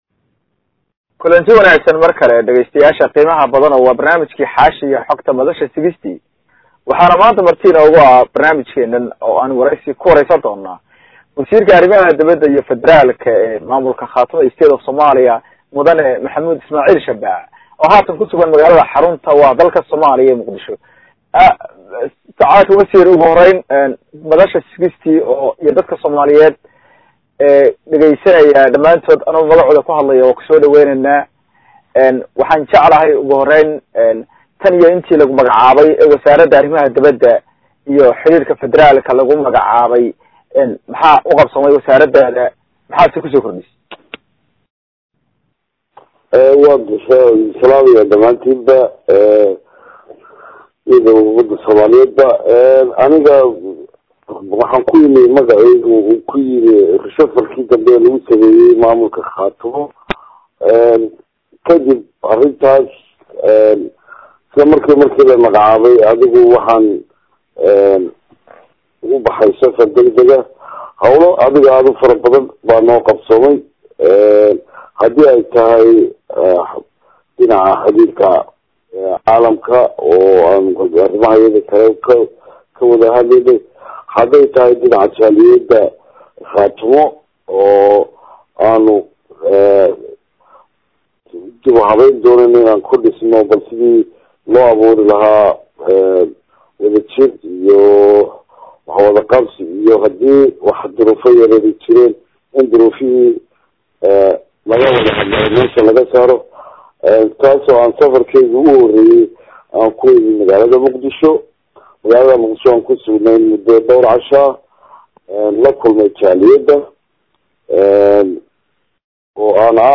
Wasiir Goboleedka Arimaha Dibada ee Khaatumo oo lagu waraystay madasha 60ka
Waraysigii-Wasiir-Maxamuud-Ismaaciil-Shabac.mp3